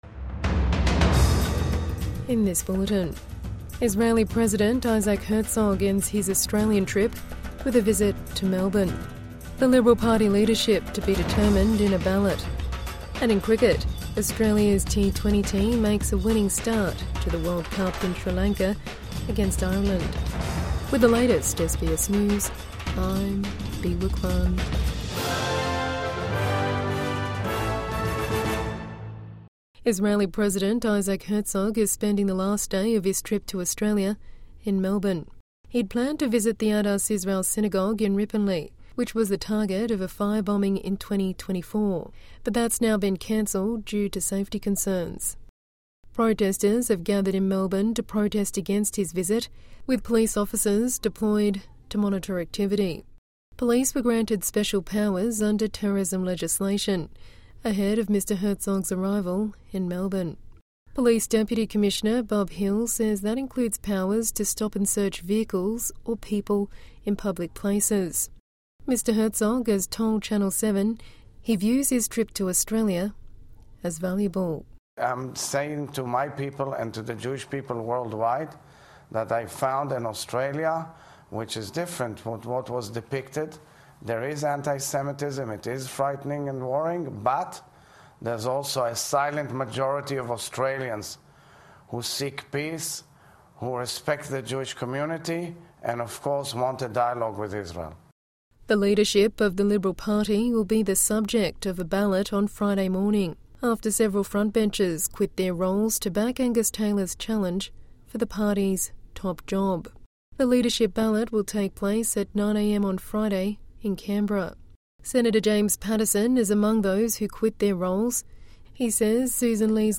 Isaac Herzog ends his Australian trip with a visit to Melbourne | Evening News Bulletin 12 February 2026